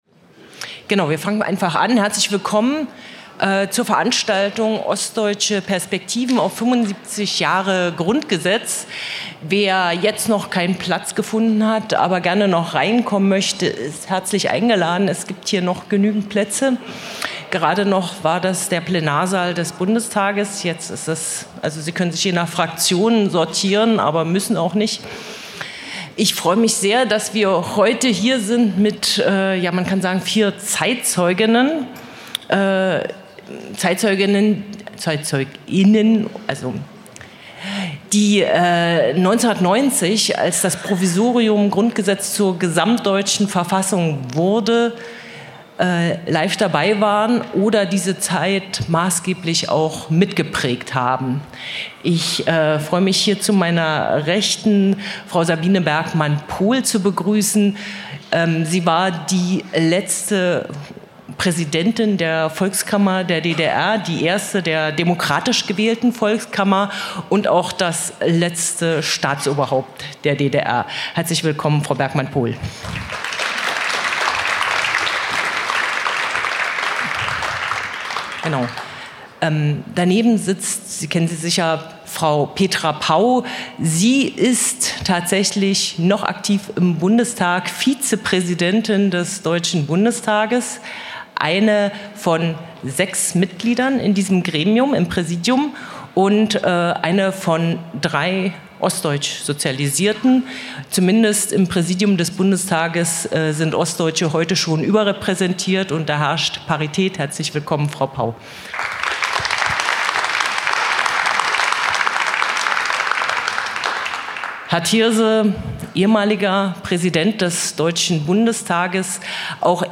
Podiumsdiskussion Ostdeutsche Perspektiven 75 Jahr Grundgesetz